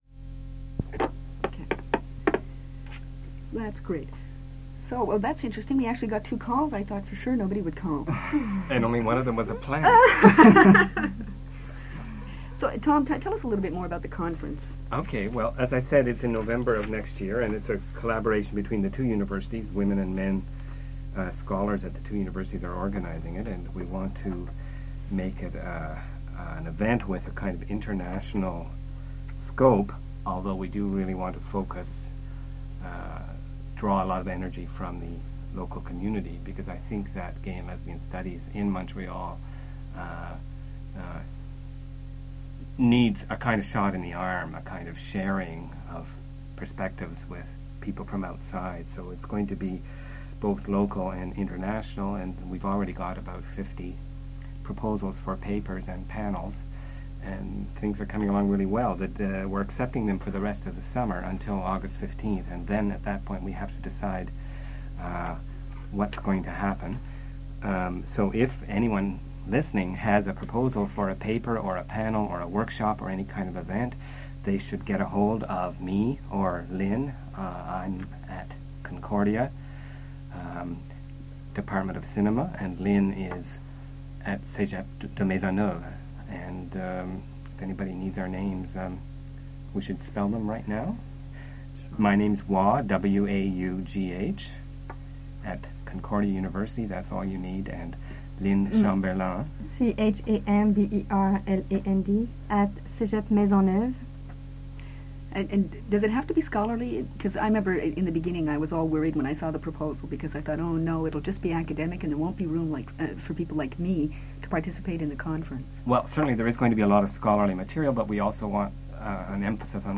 Gay Day was an annual 24 hour broadcast event held by CKUT Radio (which hosted the Dykes on Mykes broadcast), from 1989 to 1991.